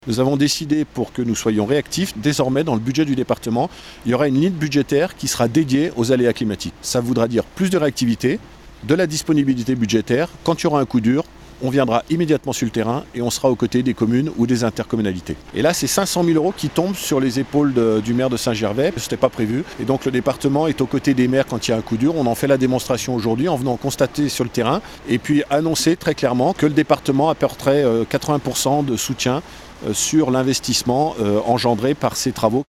Il a annoncé se mobiliser auprès des communes frappés par les aléas naturels. On écoute Martial Saddier, Président du conseil départemental de Haute-Savoie